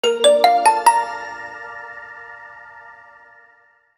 Pop Up Reveal 2 Sound Effect Download | Gfx Sounds
Pop-up-reveal-2.mp3